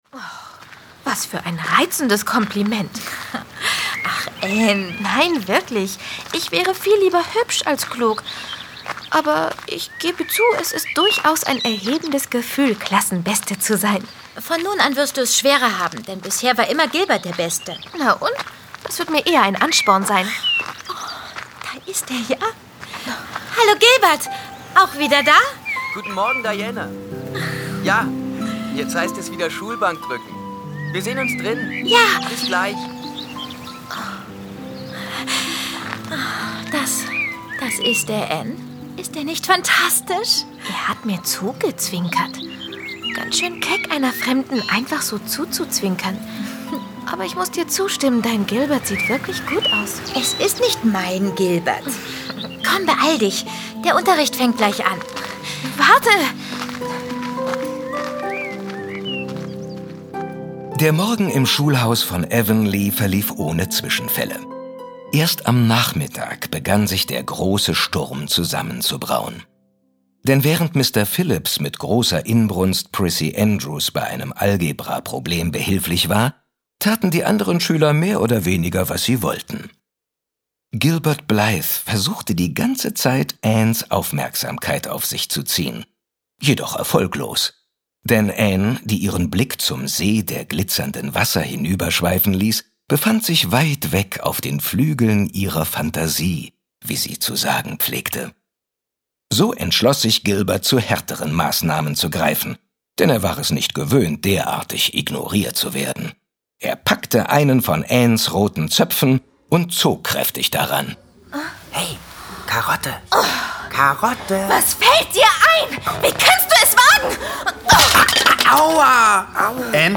Verwandte Seelen. Hörspiel. Hörspiel
Regina Lemnitz markante Stimme kennt man nicht zuletzt durch die Synchronisation von Hollywoodstars wie Whoopi Goldberg, Roseanne Barr und Kathy Bates.